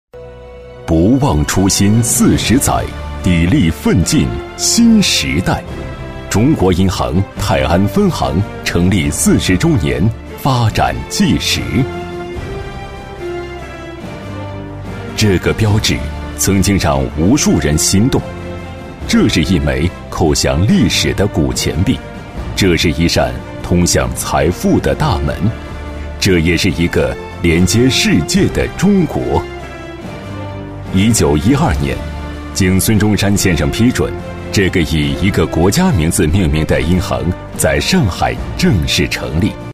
男136-企业汇报《中国银行》-大气厚重
男136-大气主流 大气浑厚
男136-企业汇报《中国银行》-大气厚重.mp3